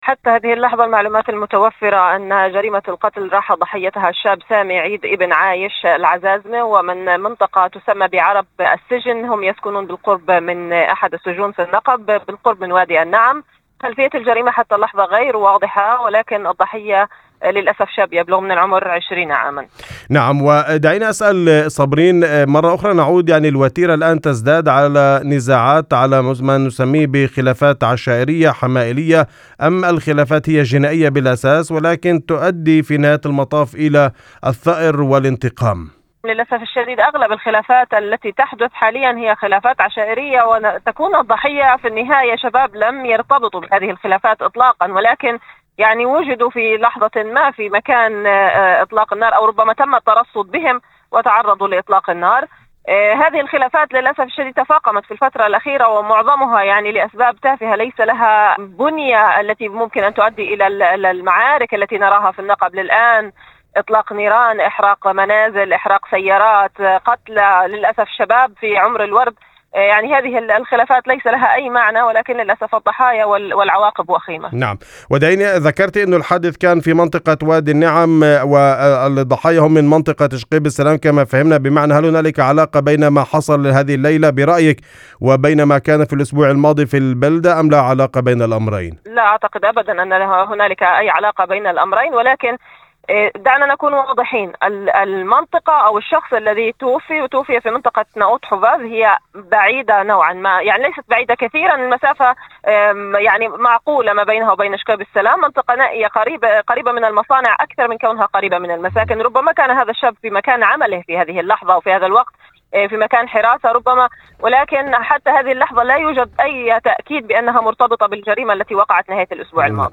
وأوضحت في مداخلة هاتفية لإذاعة الشمس، أن تفاصيل الجريمة لا تزال غير واضحة، مشيرة إلى أن المنطقة التي وقع فيها الحادث تُعد نائية نسبيًا، وهي أقرب إلى المصانع منها إلى التجمعات السكنية، ما يرجّح أن الضحية ربما كان في مكان عمله أو في موقع حراسة عند تعرضه لإطلاق النار، دون وجود تأكيد رسمي حتى الآن.